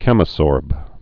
(kĕmə-sôrb)